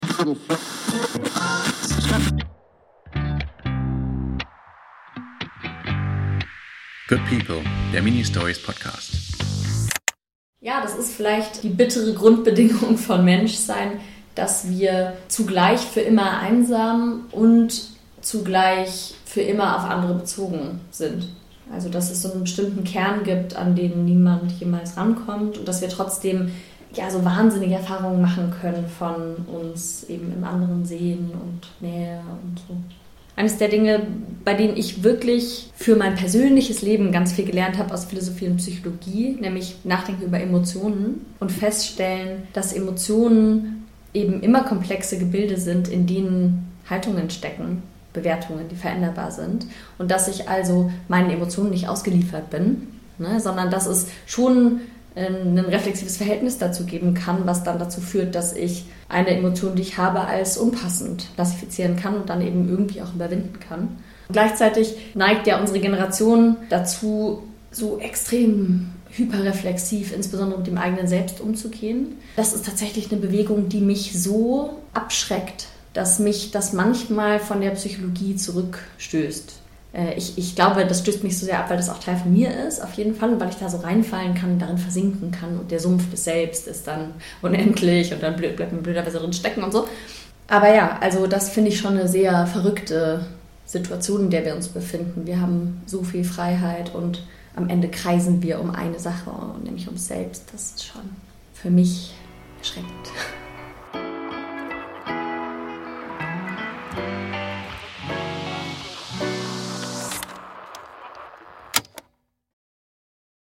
Bild, Ton und Schnitt